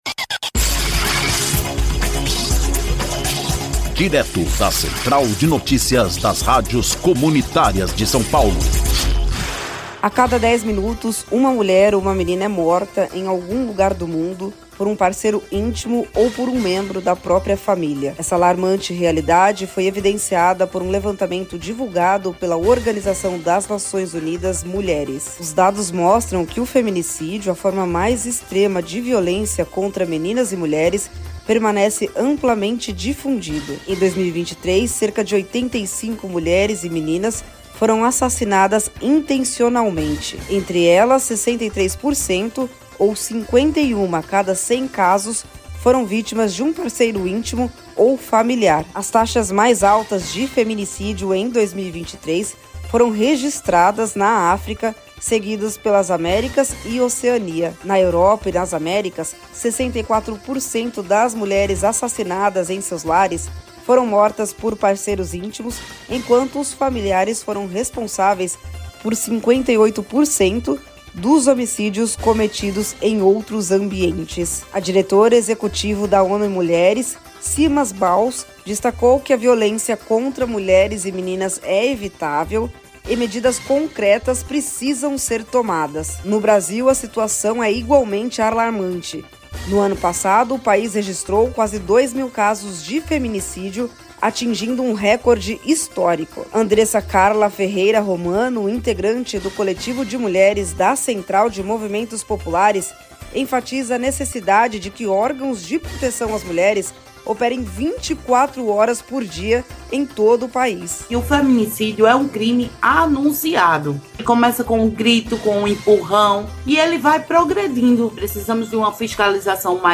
INFORMATIVO: